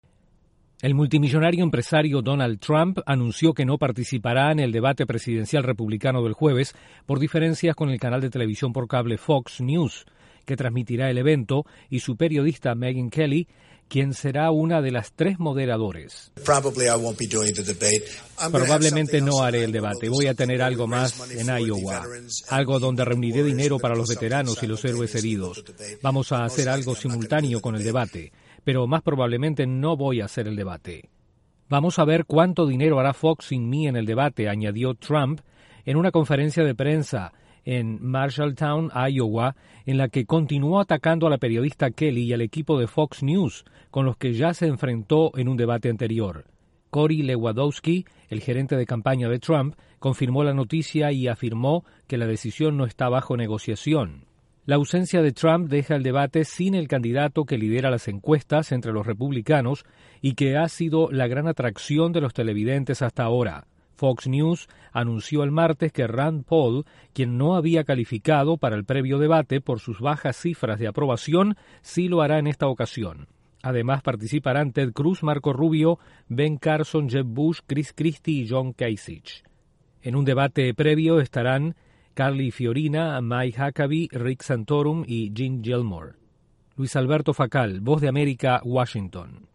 Donald Trump, el favorito para la nominación presidencial por el Partido Republicano dice que no participará en el próximo debate. Desde la Voz de América en Washington